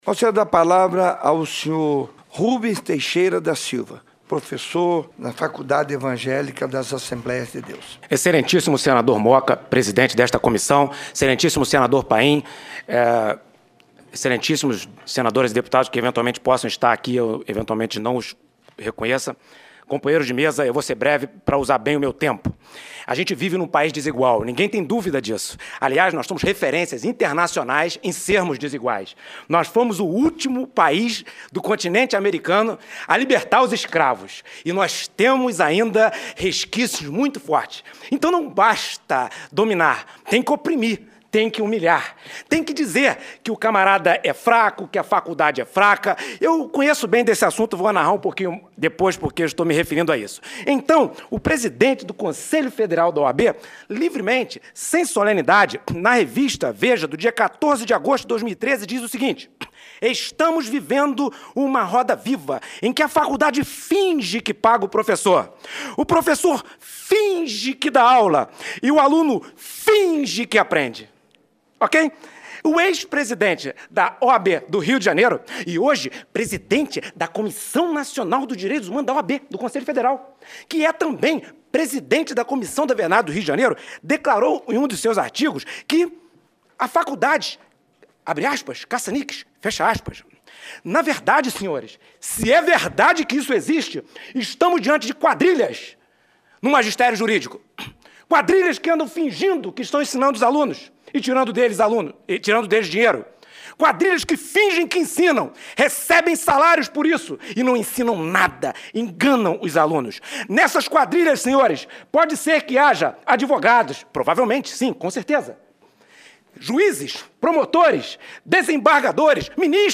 Durante debate na Comissão de Assuntos Sociais para discutir a exigência do exame da Ordem dos Advogados do Brasil para conceder o registro de advogado